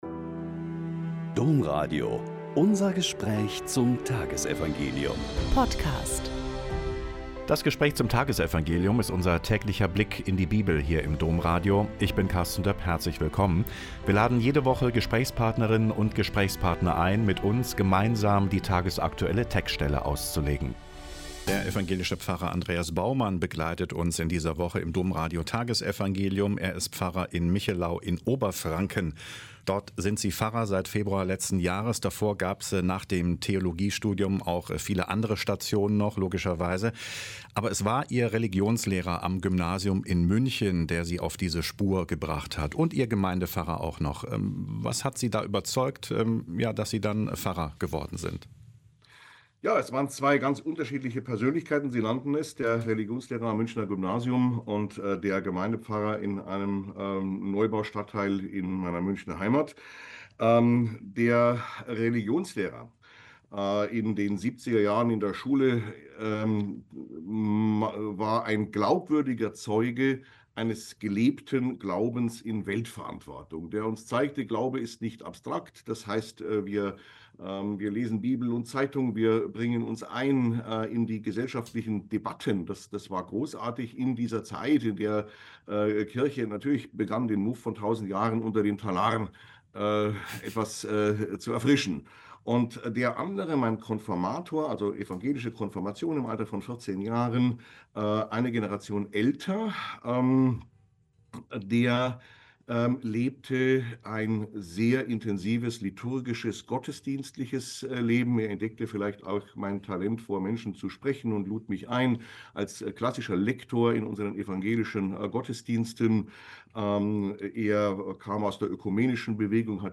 Lk 10,38-42 - Gespräch